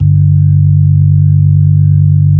Index of /90_sSampleCDs/Keyboards of The 60's and 70's - CD2/B-3_Club Slow/B-3_Club Slow